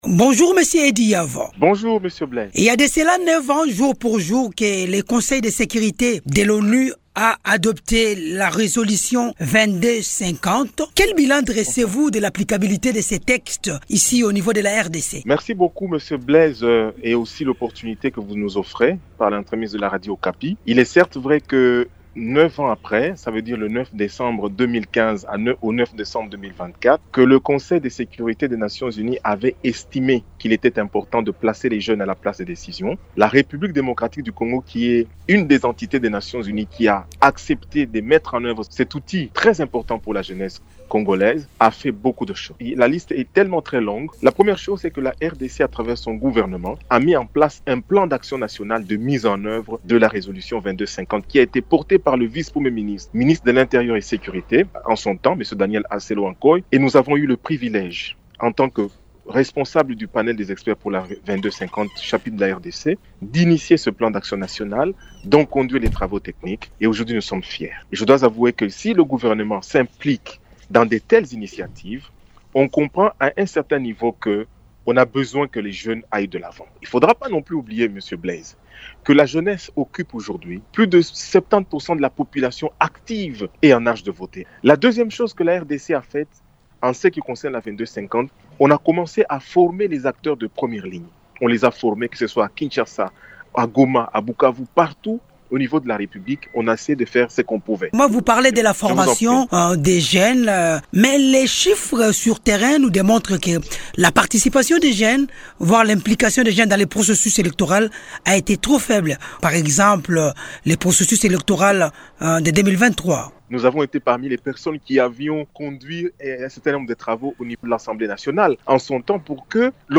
Invité de Radio Okapi, il lance cet appel en marge du 9e anniversaire de l’adoption de la Résolution 2250 au niveau du Conseil de sécurité de l’ONU.